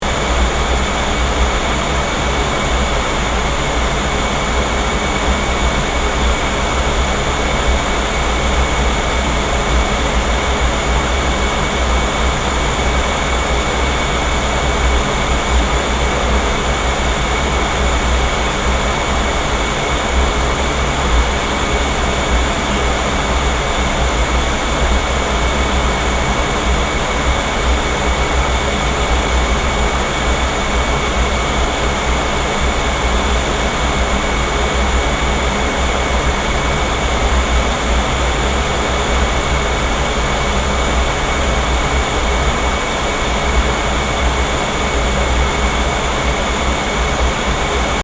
Soundscape: Paranal UT chiller
(Interior) Fan sounds are very characteristic at the telescopes’ enclosures. This sound corresponds to the air conditioning inside a Unitary Telescope (UT), an active thermal control during the day that keeps the area at 5-11°C, as this is the average exterior temperature for the beginning of the night.
ss-paranal-ut1-chiller_stereo.mp3